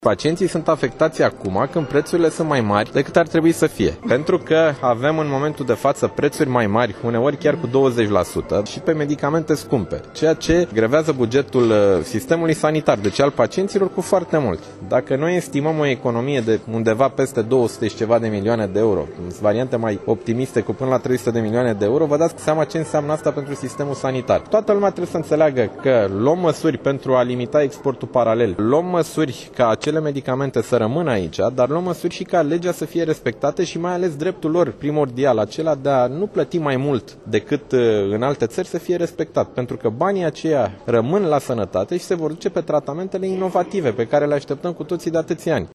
Bănicioiu mai spune că pacienţii nu au motive de îngrijorare şi că medicamentele nu vor dispărea de pe piaţă: